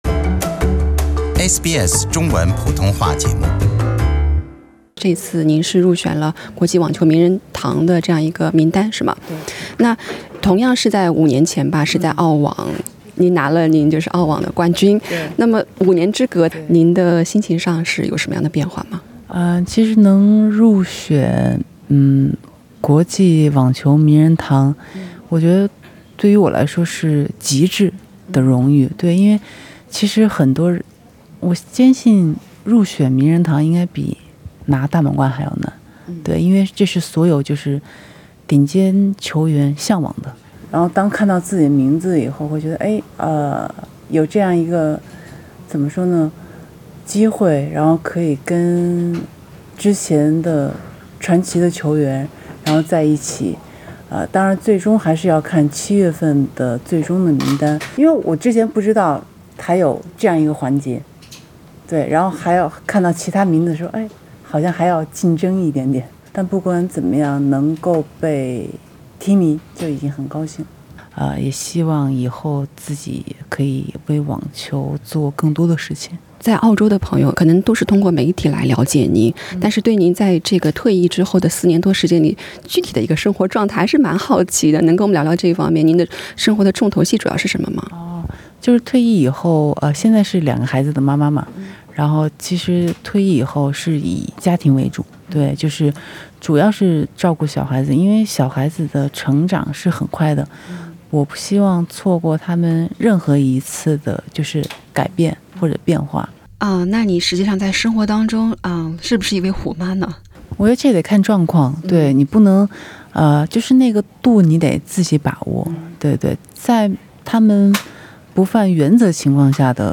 SBS普通话节目记者在澳网现场与李娜面对面，听她讲述退役四年多里的生活，显然比起恋战球场，李娜更享受身为人母的角色，她直言，“可能如果我在事业上更成功，我就不会有孩子了”。而说到陈可辛执导的传记电影《李娜传》，李娜透露这部酝酿许久的电影或许最早会在今年年底上映，并且精雕细琢，开拍前就和陈可辛“聊了快四年的时间”。